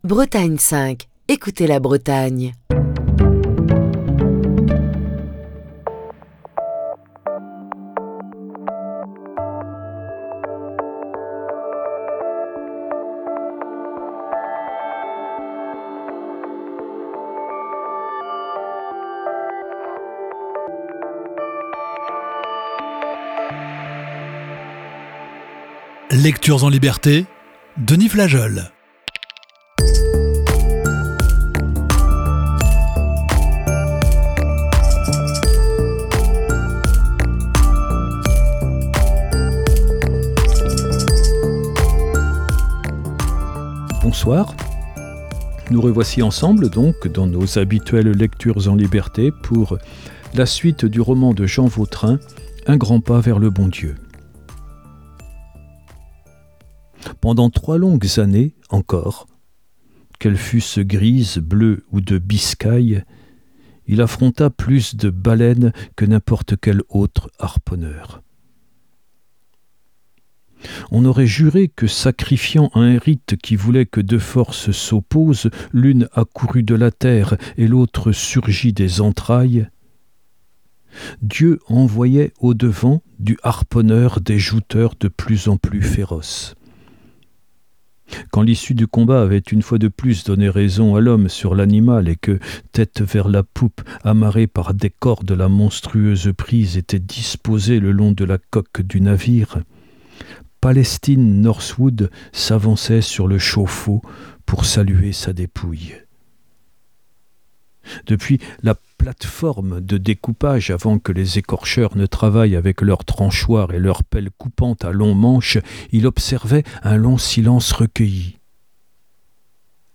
Émission du 28 juin 2024.